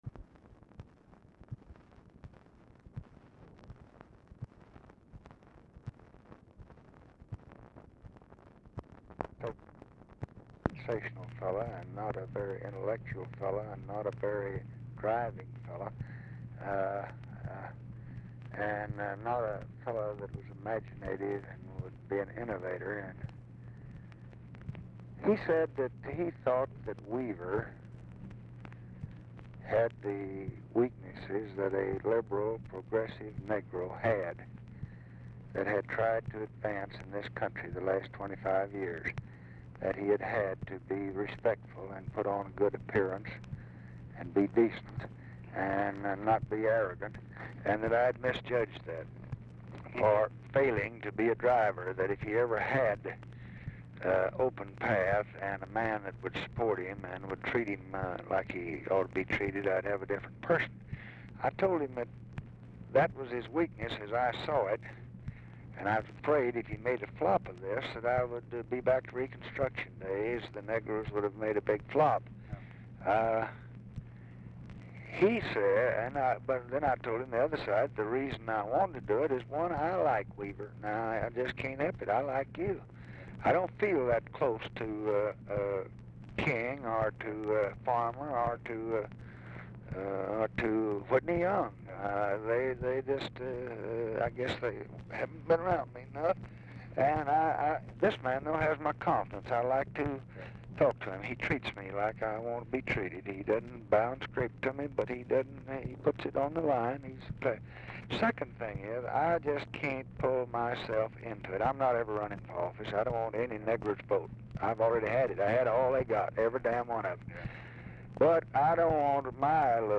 Telephone conversation # 9430, sound recording, LBJ and ROY WILKINS, 1/5/1966, 4:55PM | Discover LBJ
Format Dictation belt
Location Of Speaker 1 Oval Office or unknown location
Specific Item Type Telephone conversation Subject Appointments And Nominations Civil Rights Congressional Relations Housing Lbj Personal National Politics Texas Politics Urban Affairs Welfare And War On Poverty